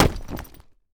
household
Duffle Bag Drop Cement Floor 4